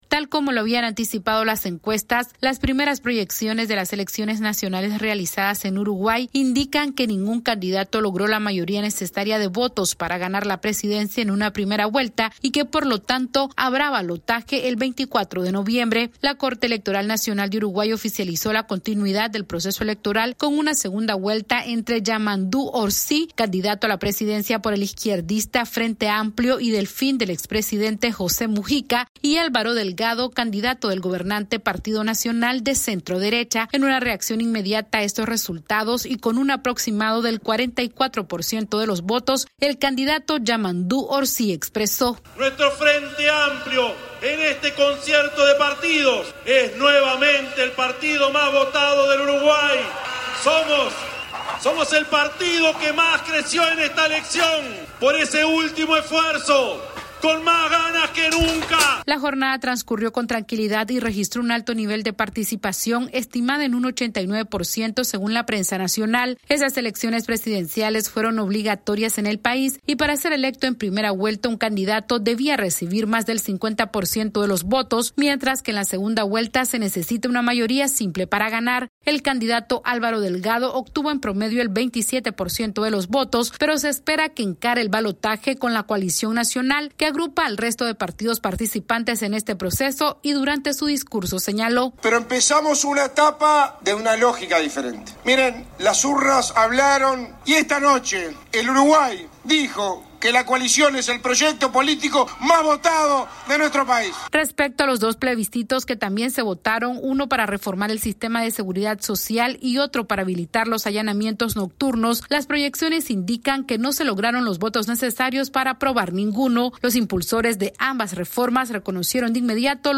Las elecciones presidenciales en Uruguay se definirán en una segunda vuelta luego que ninguno de los candidatos alcanzara los votos necesarios para ganar la jefatura de Estado. Esta es una actualización de nuestra Sala de Redacción...